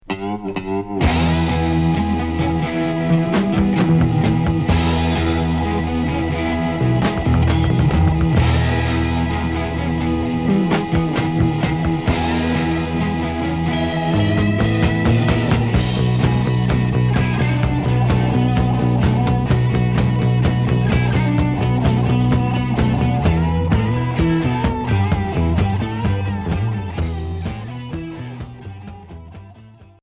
Heavy rock Deuxième 45t retour à l'accueil